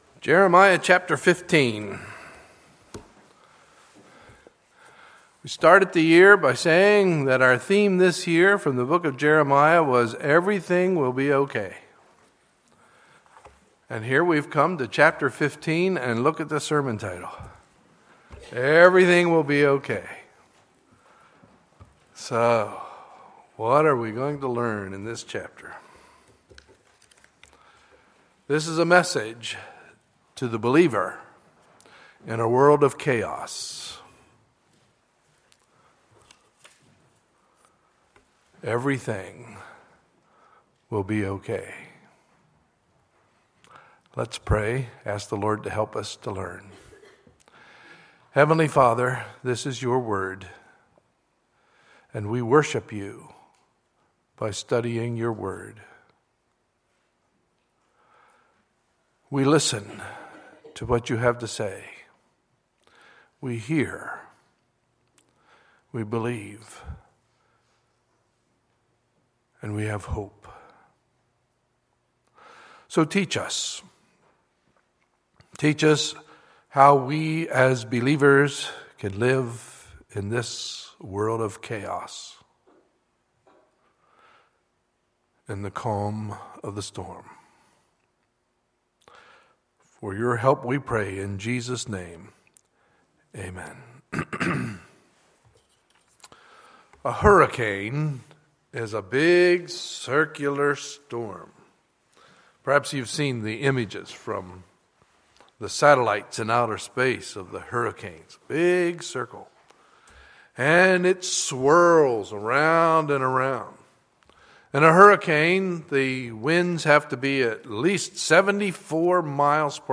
Sunday, May 3, 2015 – Sunday Morning Service